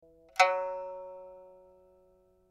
pipa6.mp3